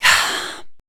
WHISPER 04.wav